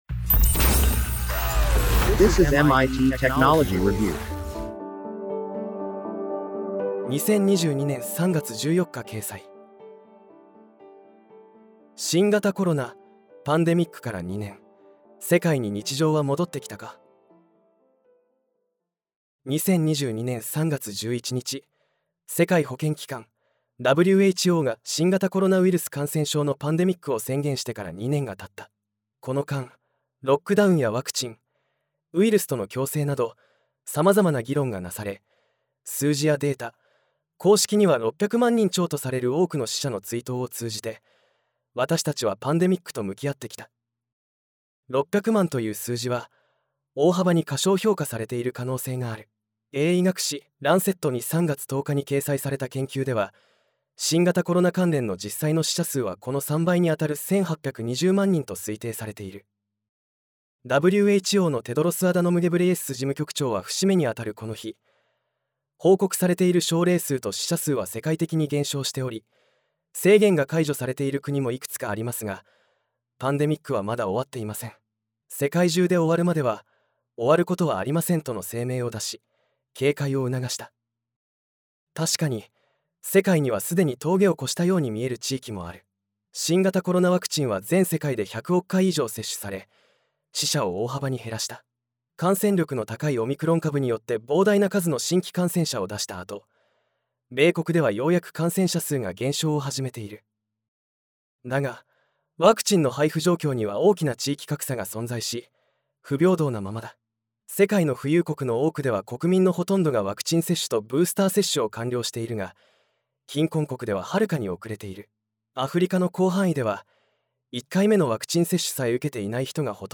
なお、本コンテンツは音声合成技術で作成しているため、一部お聞き苦しい点があります。